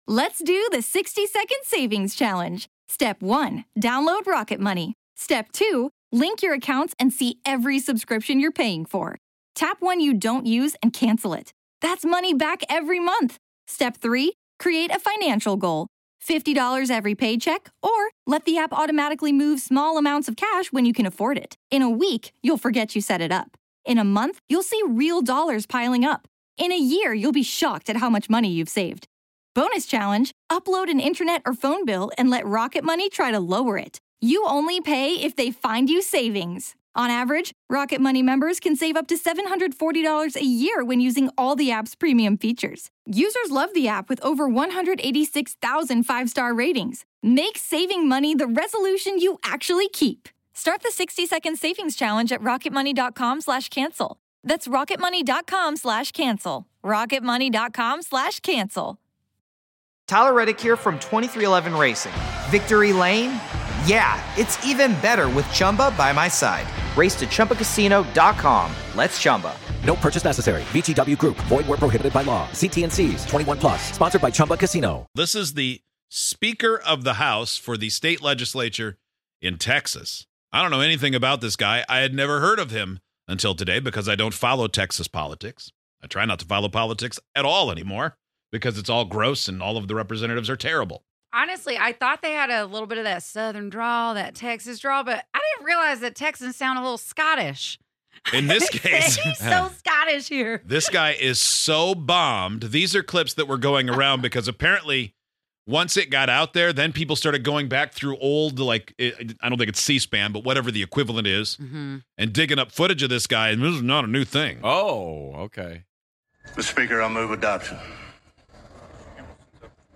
Listen and see if you can figure out what the hell this guy is saying.